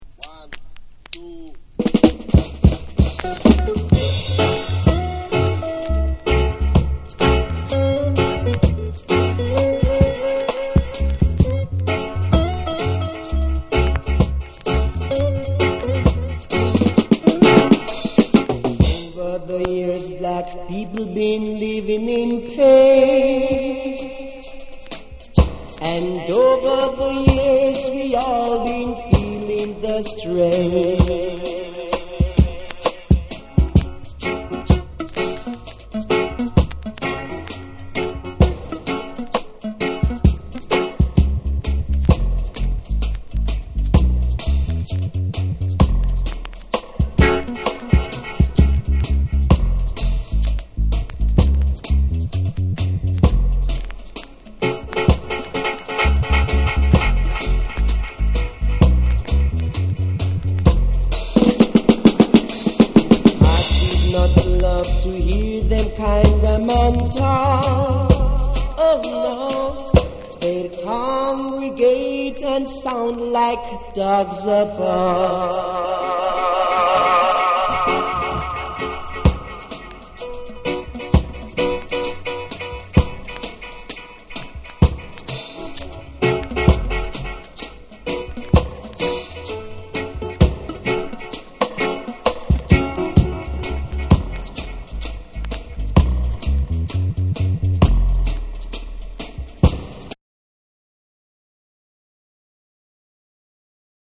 REGGAE & DUB / ROOTS & CULTURE